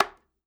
Percussion #07.wav